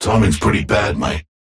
Bebop voice line - Timing's pretty bad, mate.